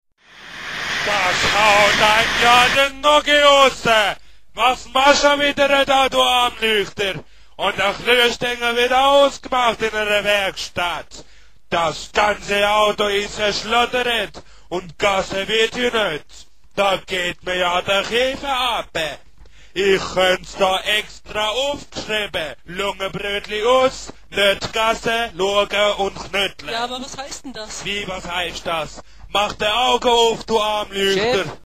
Is it Schweizerdeutsch (Swiss German)?
It sounds like it’s relatively close to standard German, so it’s probably one of the northern dialects.
And it contains a lot of swearing too!!